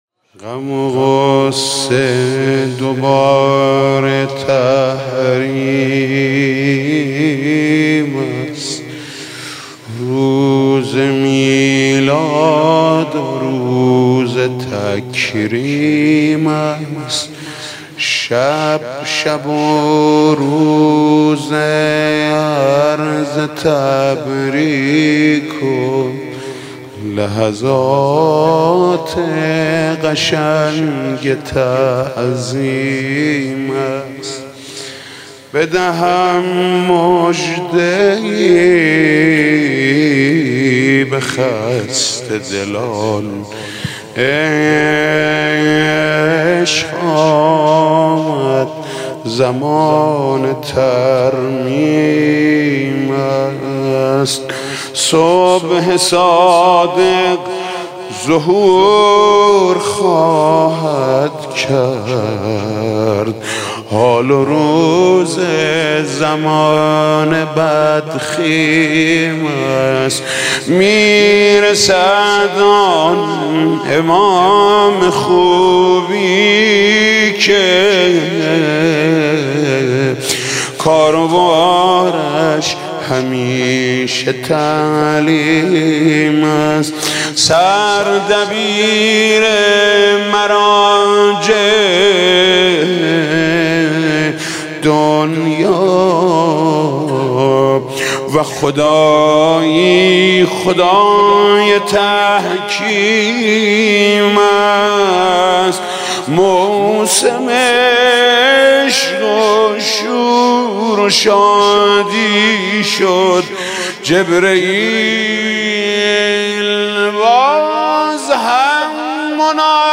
دانلود مولودی پیامبر اکرم (ص) و امام جعفر صادق (ع) محمود کریمی